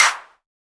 normal-hitclap.ogg